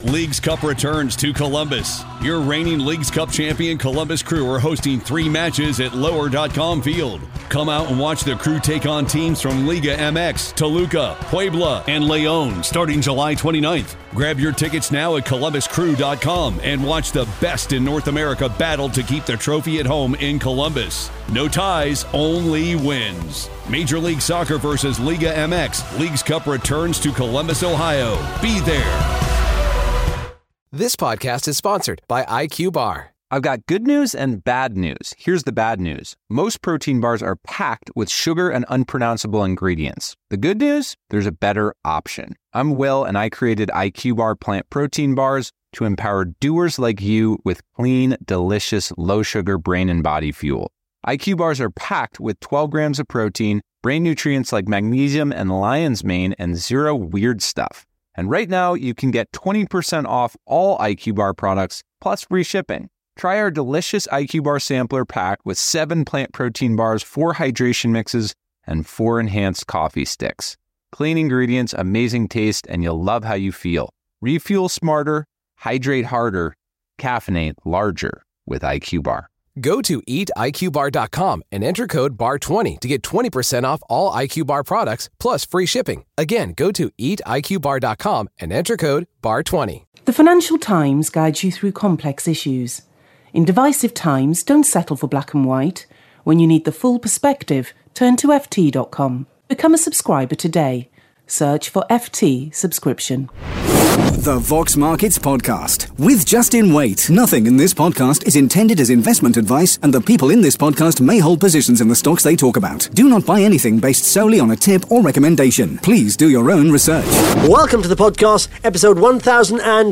(Interview starts at 1 minute 40 seconds)